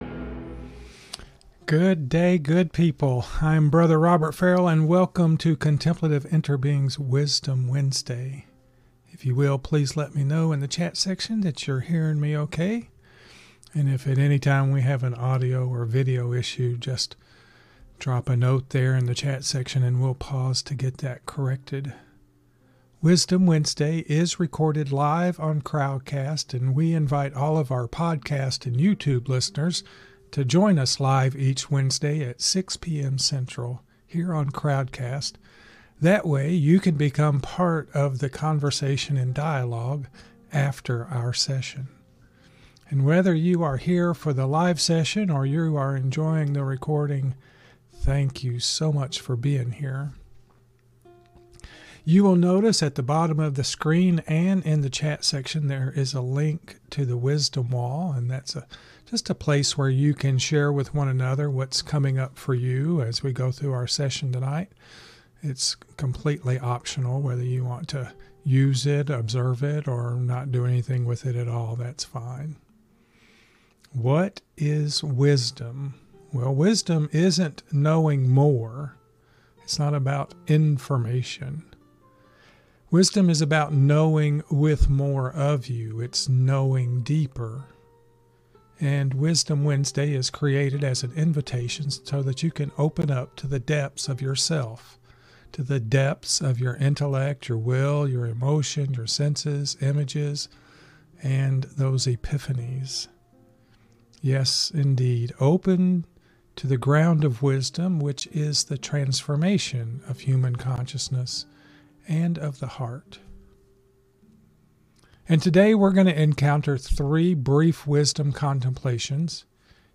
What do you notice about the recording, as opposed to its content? Wisdom Wednesday is recorded live on Crowdcast.